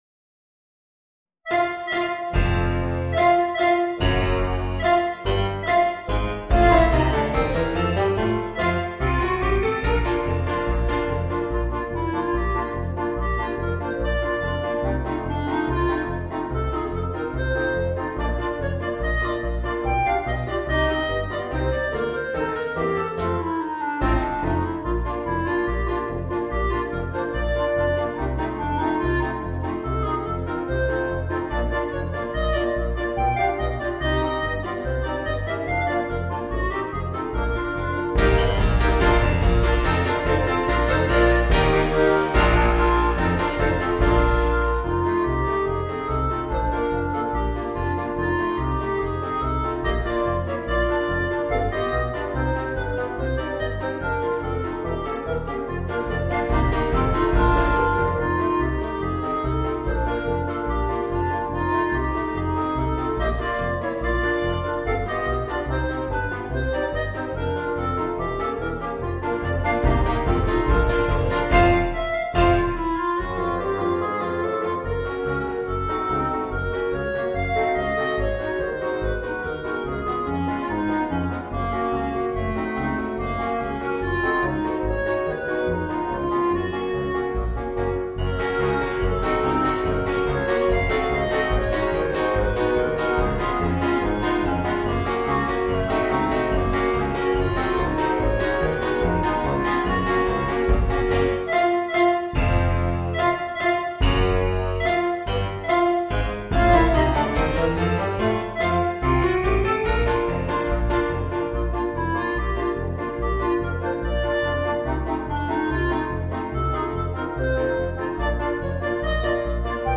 Voicing: Woodwind Quartet and Rhythm Section